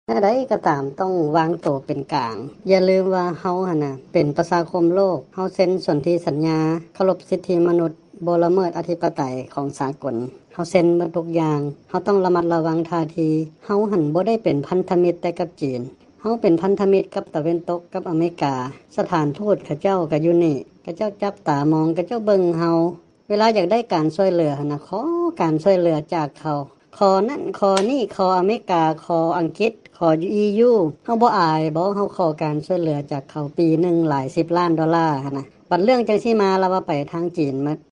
ສຽງຂອງນັກປັນຍາຊົນລາວກ່ຽວກັບການເອົາທ່າທີຂອງລາວ